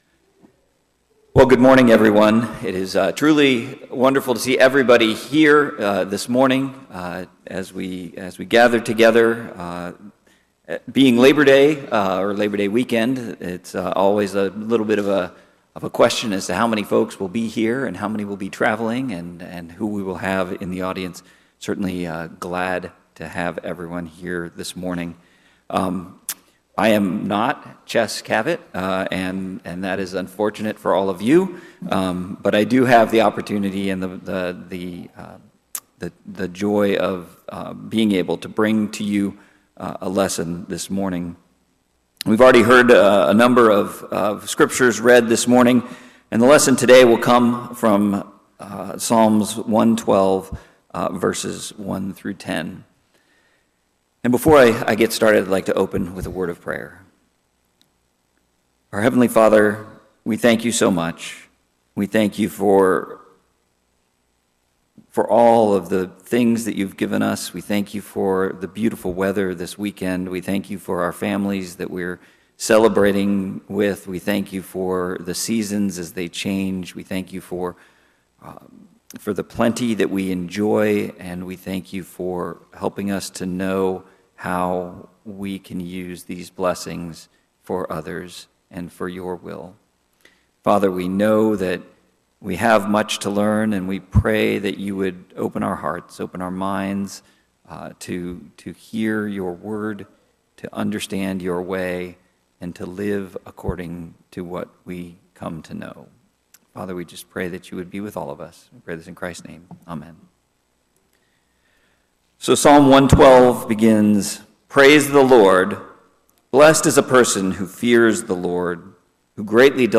The sermon encourages a lifestyle marked by grace, hospitality, and reliance on God’s favor.